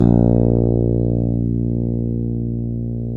Index of /90_sSampleCDs/Roland - Rhythm Section/BS _E.Bass 3/BS _Ch.Fretless